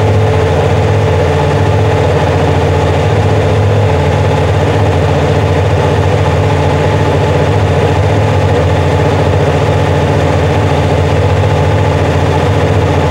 idle2.wav